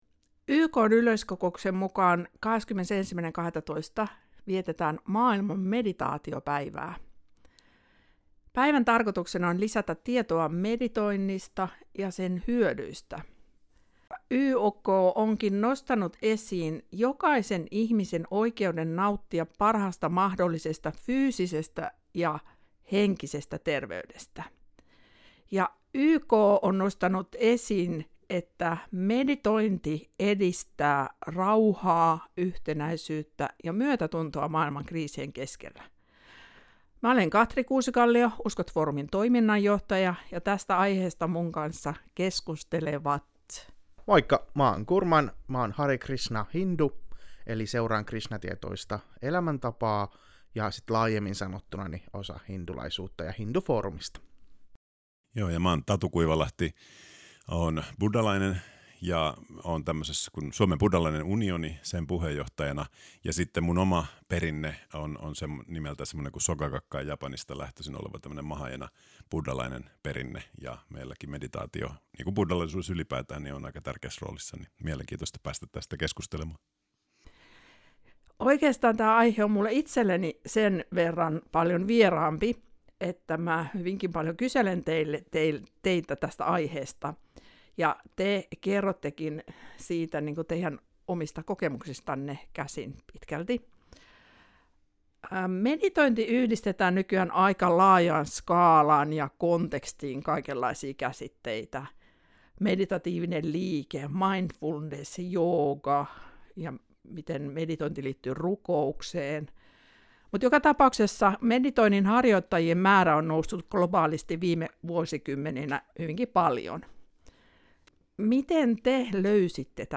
keskustelevat